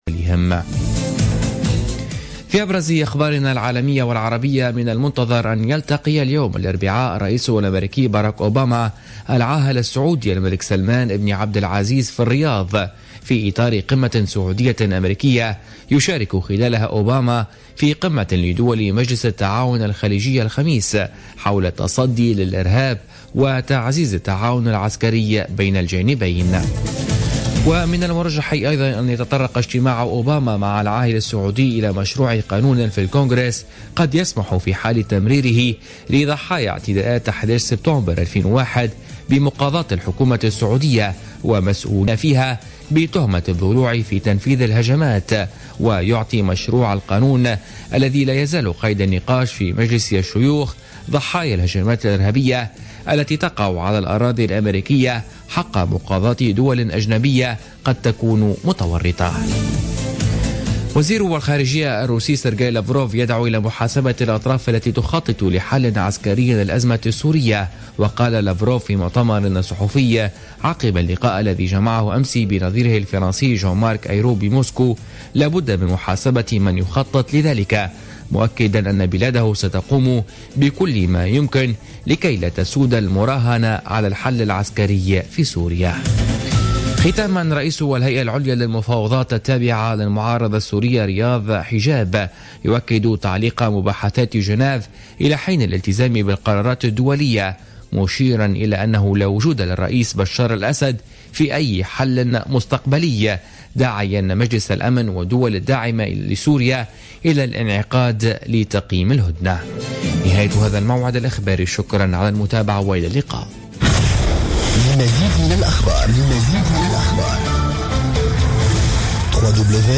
Journal Info 00h00 du mercredi 20 avril 2016